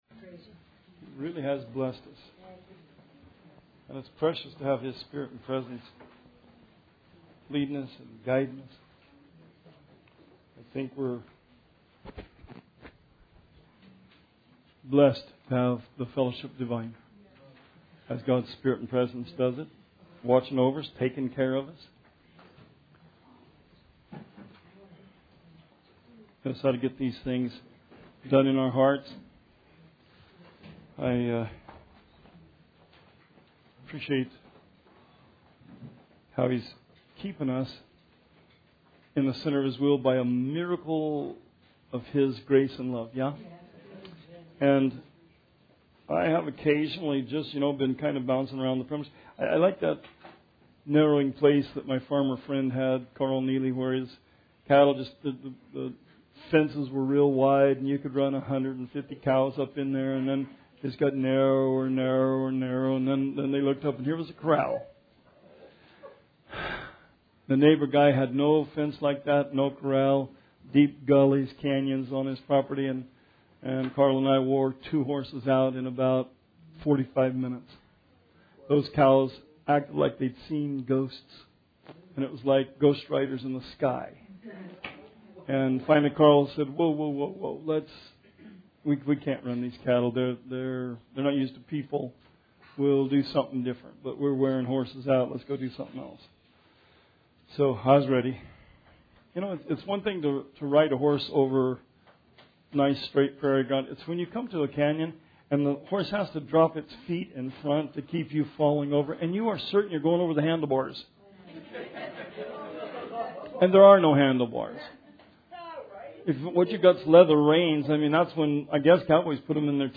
Bible Study 8/28/19